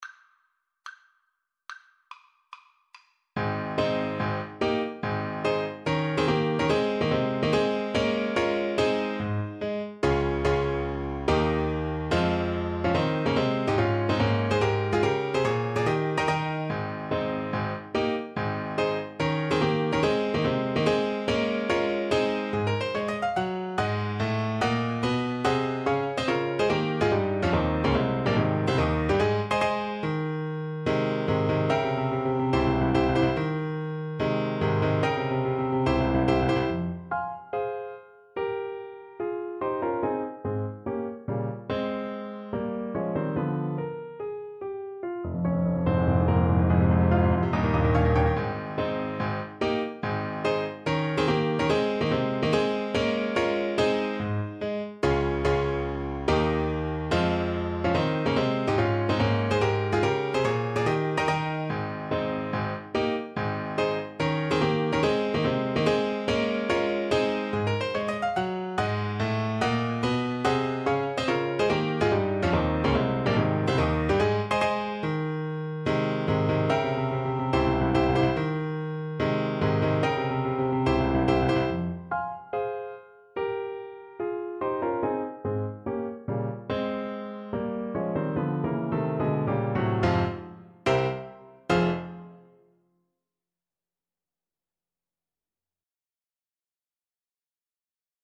Allegro non troppo (=72) (View more music marked Allegro)
Classical (View more Classical Saxophone Music)